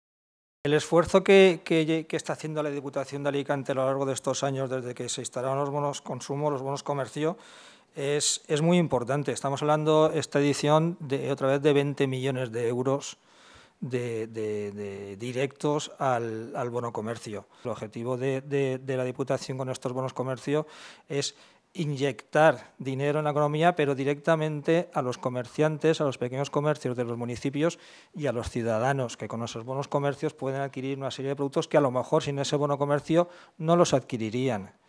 Corte-audio-diputado.mp3